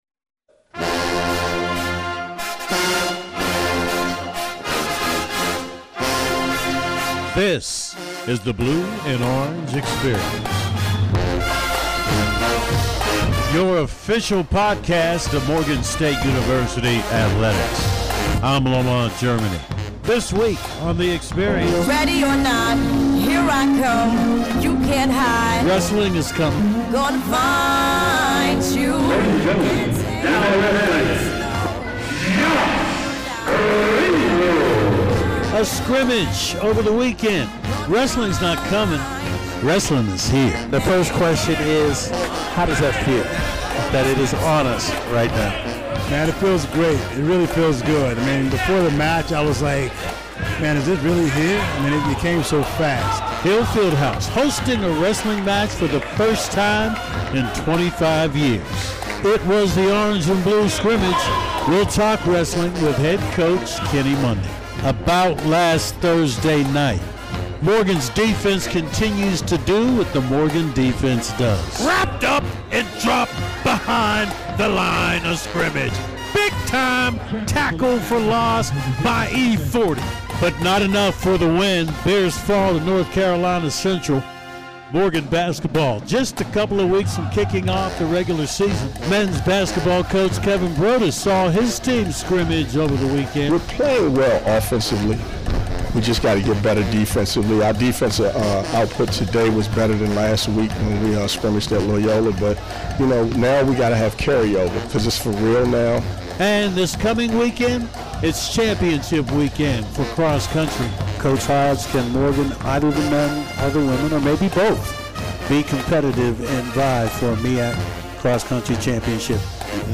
It explores the teams. coaches, student-athletes, alumni, stories and traditions of Morgan State University athletics. This edition of the BLUE & ORANGE Experience looks back on the week in Morgan State athletics and features an interview with first year Wrestling Head Coach Kenny Monday.